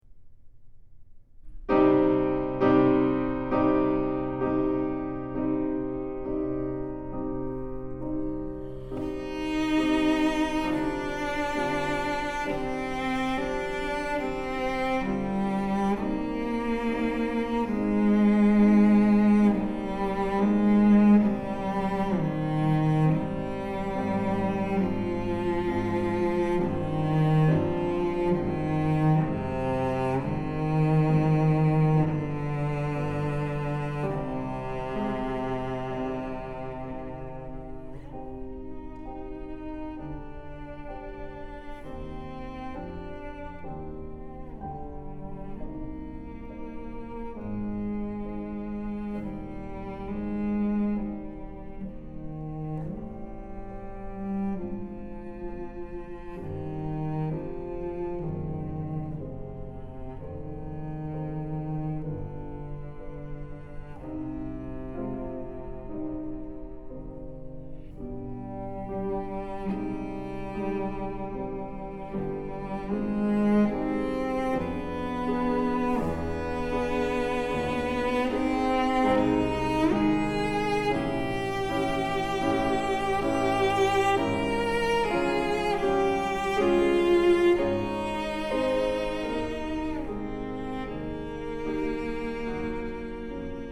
☆才華洋溢、沈穩內斂的大師級演奏風範，不必刻意炫技卻更教人心動！
☆極簡錄音處理，再現最真實質樸且極具典雅氣質的樂器溫潤光澤。